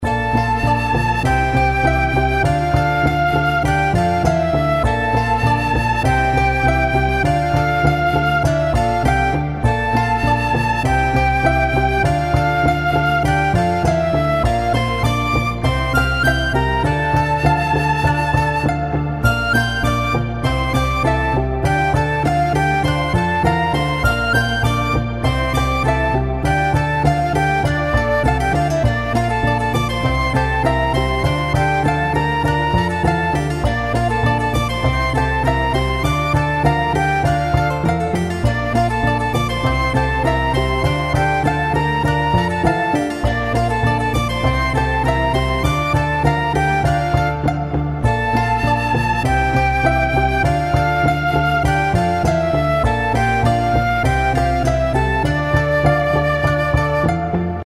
それぞれ１ループの音源です♪